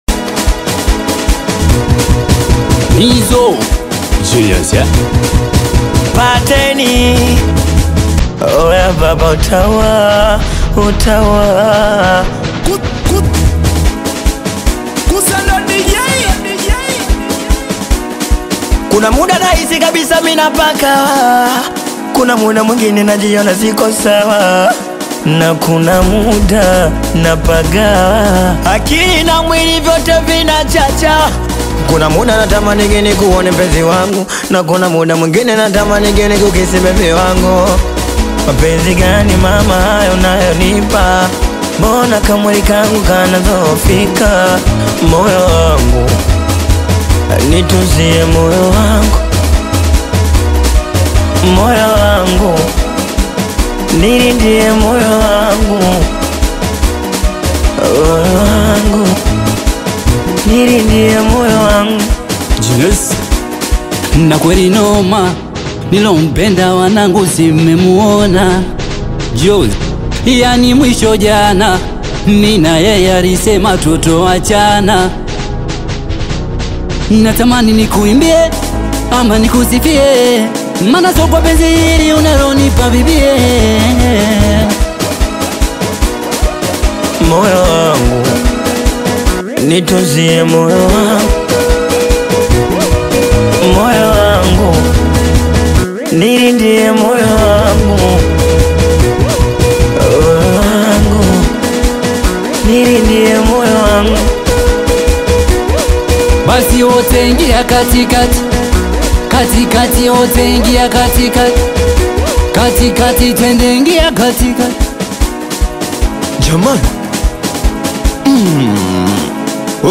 soulful Bongo Flava single
Genre: Singeli